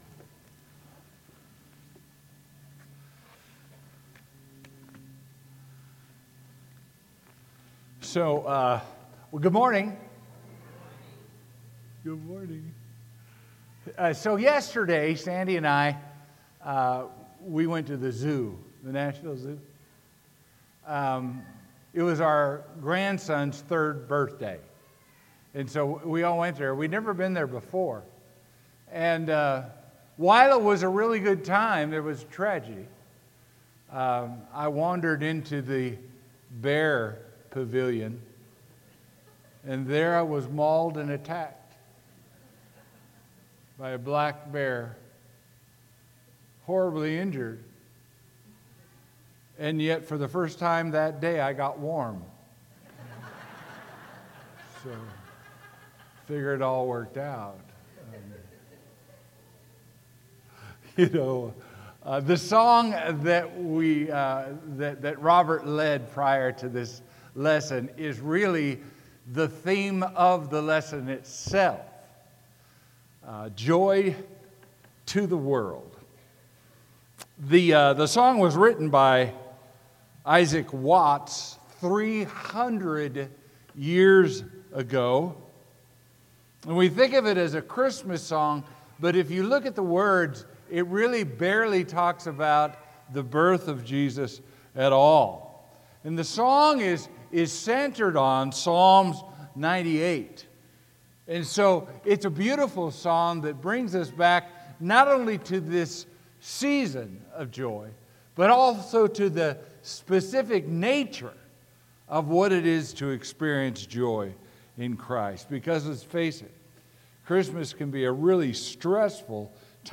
Sermon: “Joy to the World”